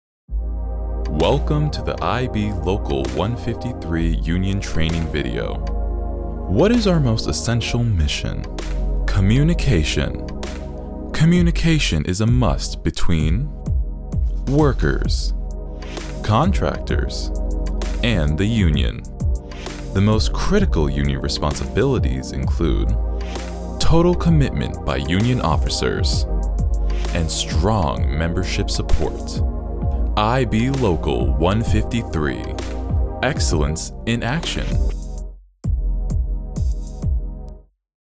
Demos
English - USA and Canada
Young Adult
Middle Aged